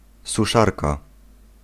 Ääntäminen
Ääntäminen Tuntematon aksentti: IPA: [suˈʂarka] Haettu sana löytyi näillä lähdekielillä: puola Käännös Konteksti Substantiivit 1. dryer 2. hairdryer brittienglanti 3. radar gun slangi Suku: f .